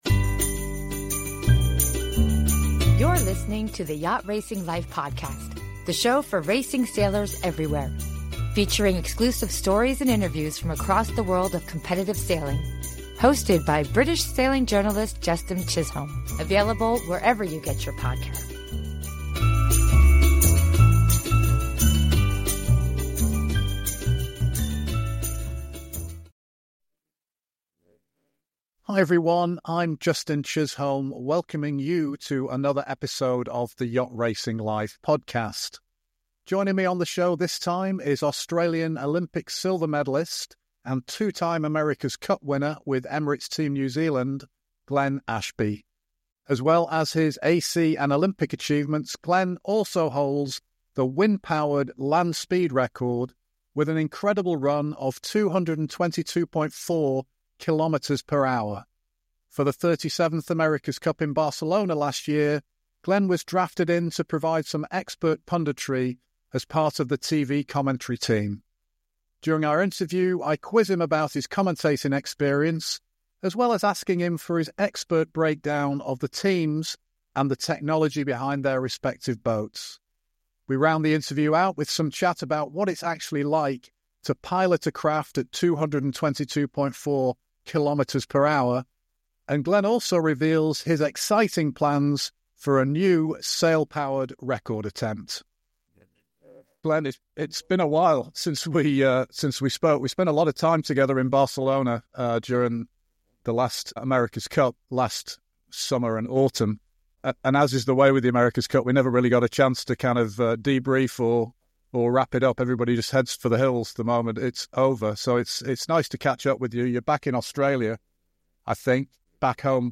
During the interview Glenn is quizzed him about his commentary booth experience and shares his expert breakdown of the six competing teams and the technology behind their respective boats.